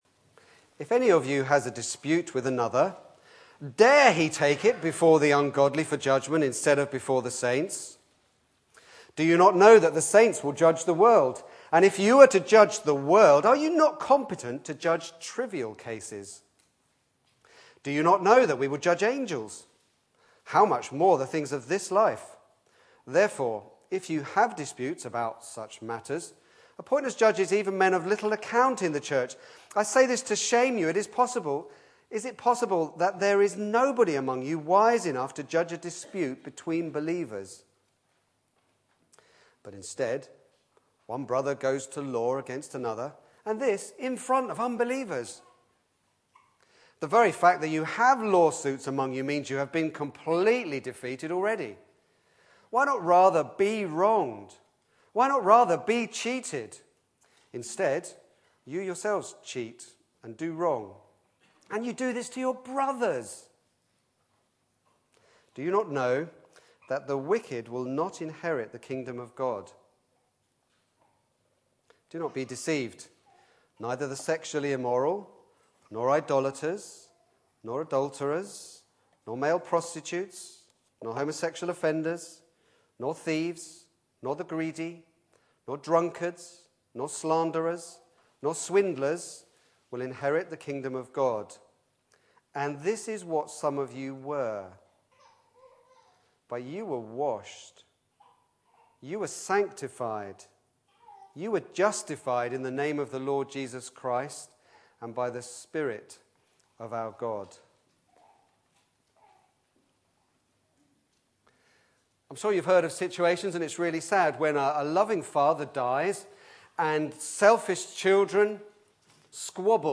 Back to Sermons Brothers at Law